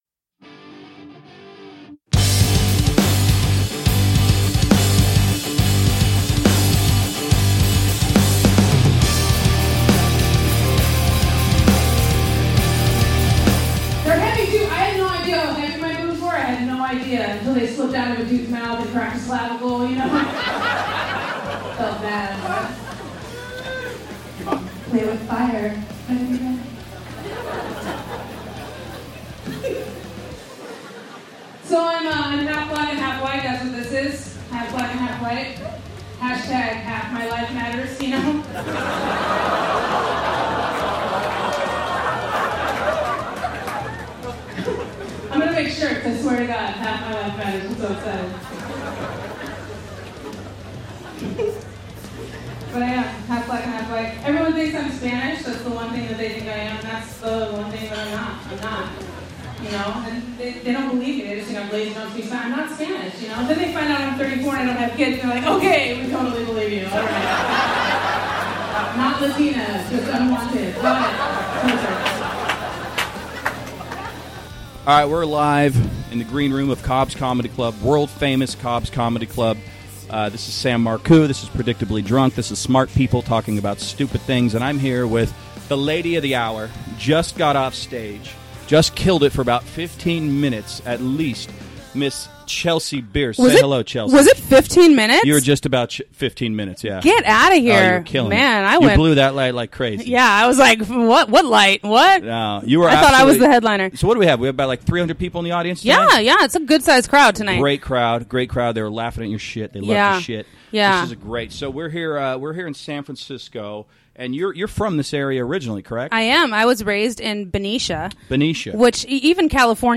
in the green room at Cobbs Comedy Club in San Francisco